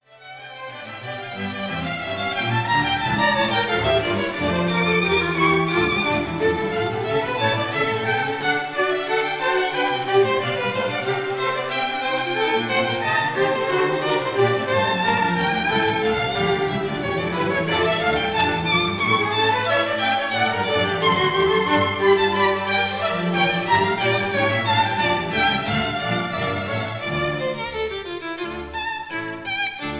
Concerto in D minor for two violins and strings
conductor
historic 1932 recording